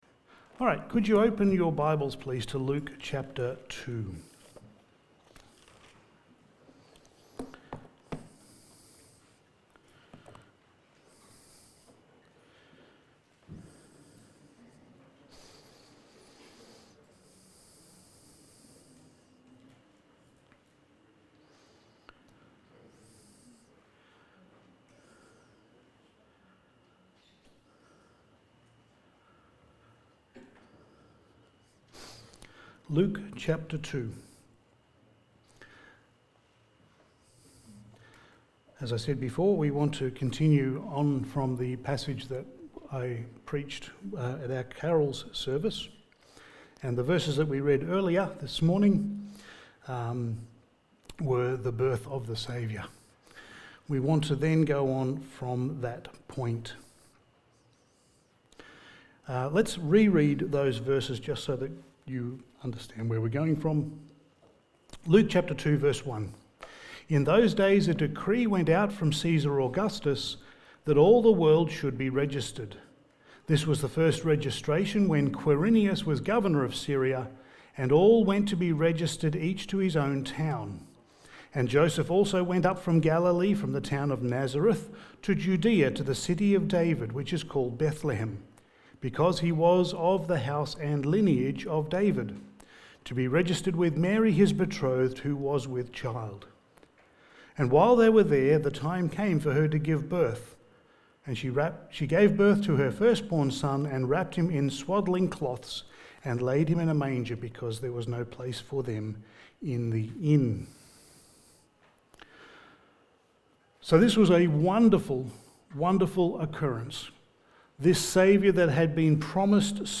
Passage: Luke 2:8-20 Service Type: Special Event